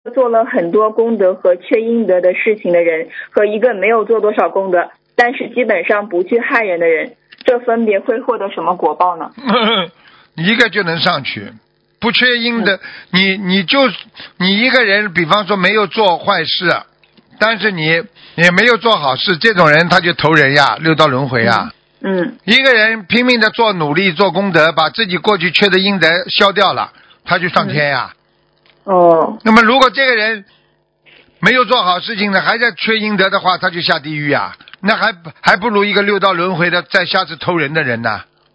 目录：☞ 2019年12月_剪辑电台节目录音_集锦